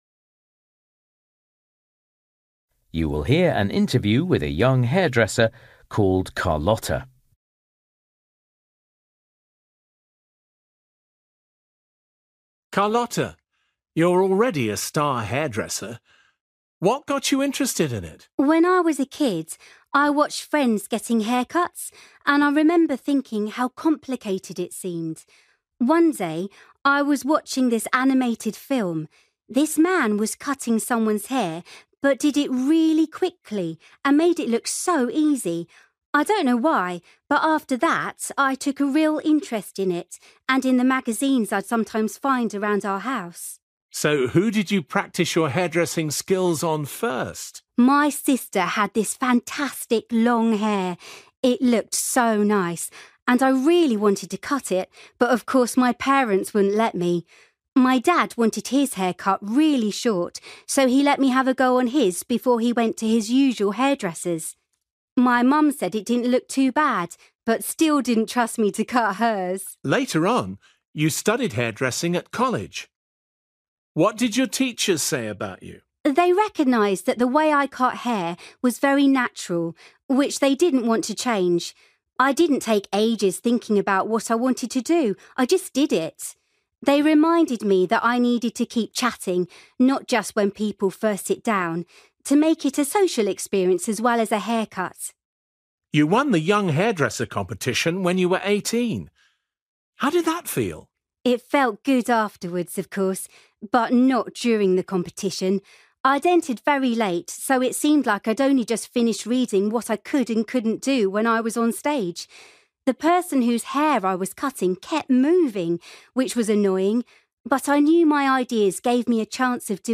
Listening: a young hairdresser